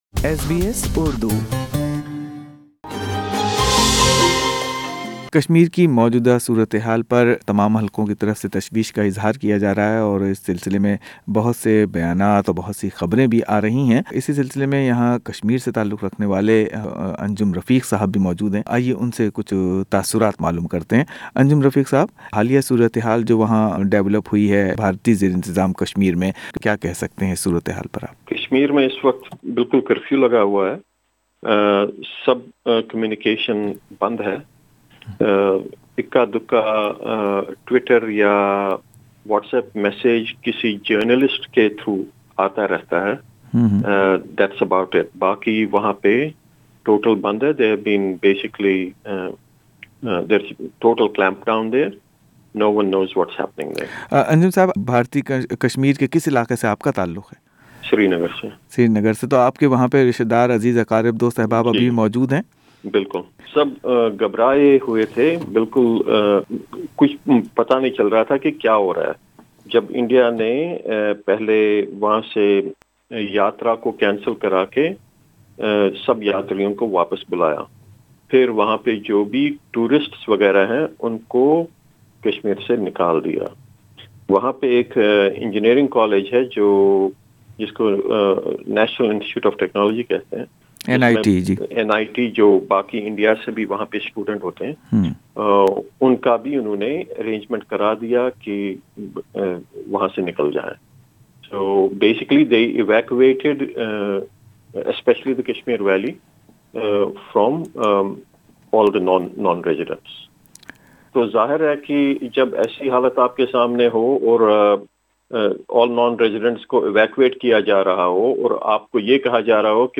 بھارت نے حال ہی میں کشمیر سے متعلق آرٹیکل 370 ختم کردیا ہے۔ کشمیر کے معاملے پر ایس بی ایس اردو نے آسٹریلیا میں کشمیر سے تعلق رکھنے والے شہری سے گفتگو کی.